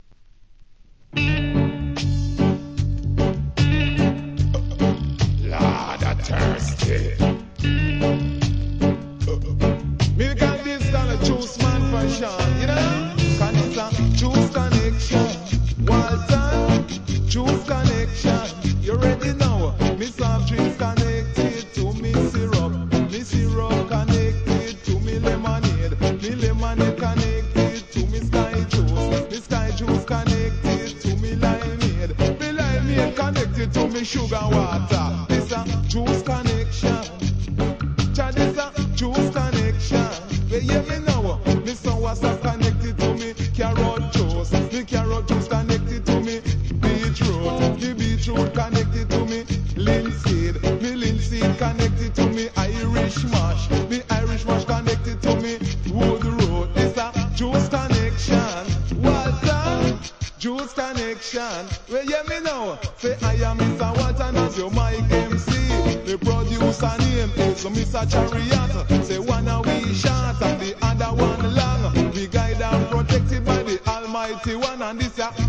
HIP HOP/REGGARE/SOUL/FUNK/HOUSE/